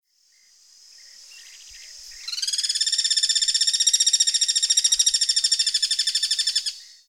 Pirincho (Guira guira)
Nombre en inglés: Guira Cuckoo
Fase de la vida: Adulto
Localidad o área protegida: Delta del Paraná
Condición: Silvestre
Certeza: Observada, Vocalización Grabada